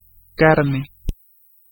Ääntäminen
France: IPA: [lə kɔʁ]